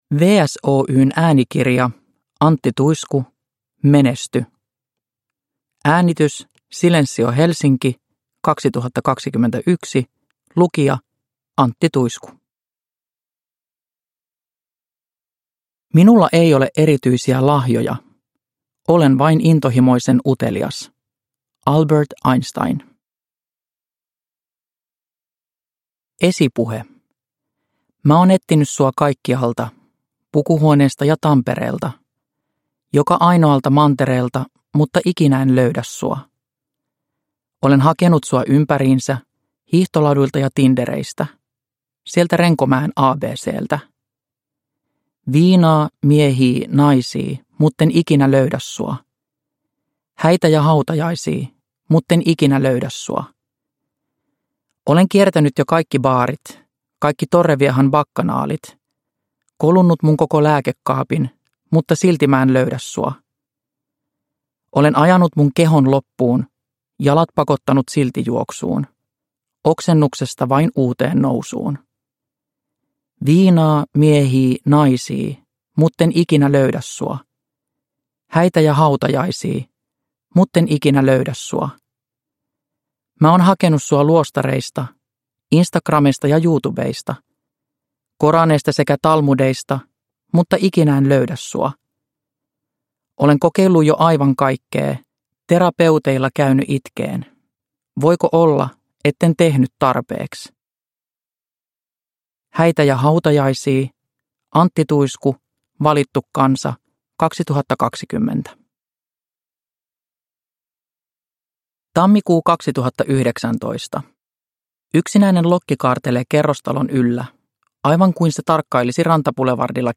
Menesty! – Ljudbok – Laddas ner
Uppläsare: Antti Tuisku